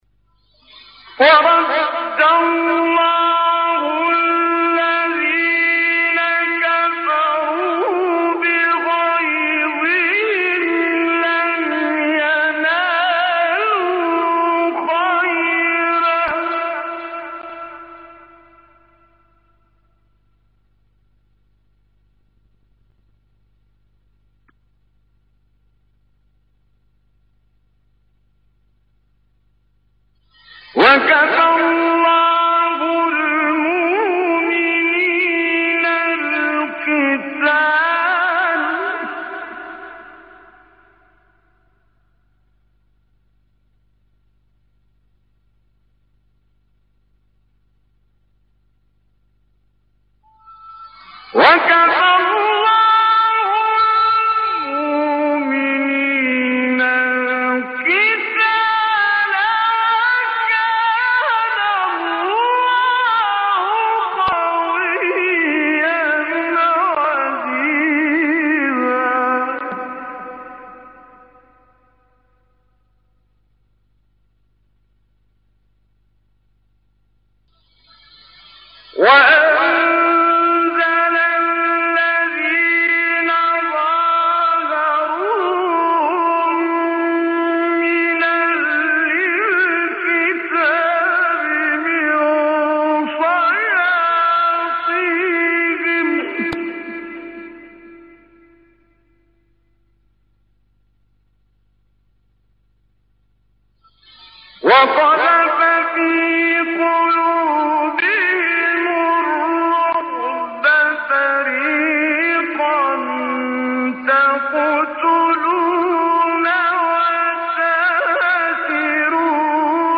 آیه 25-29 سوره احزاب استاد کامل یوسف | نغمات قرآن | دانلود تلاوت قرآن